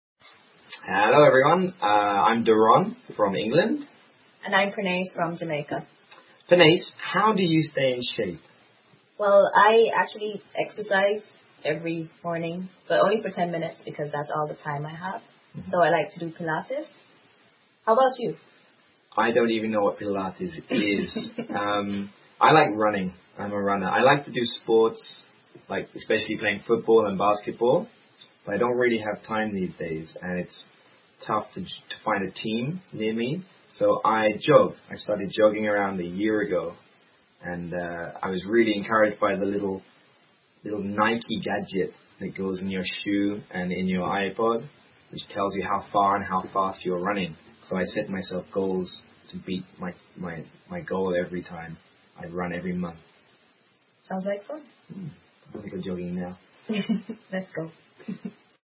实战口语情景对话 第1229期:How do you stay in shape? 保持身材的方法